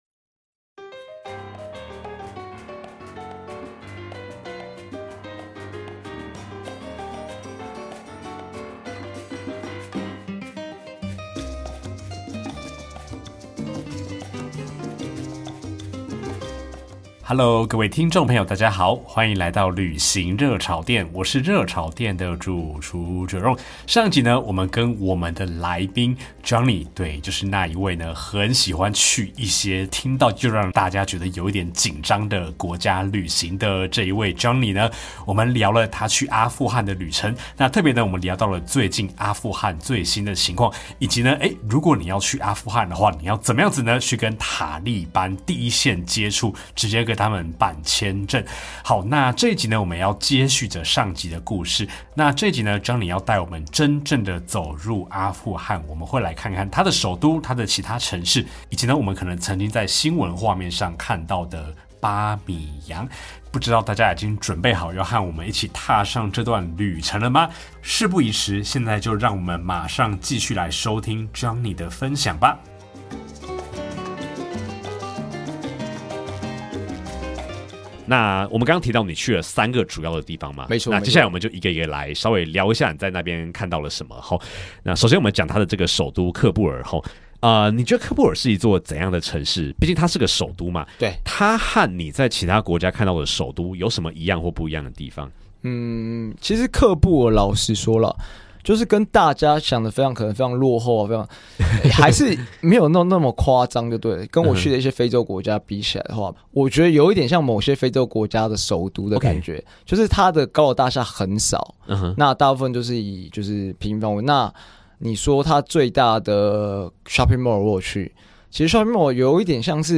基層社畜一枚，用下班後的剩餘精力旅行與做節目，大多數的單集都是自己講的。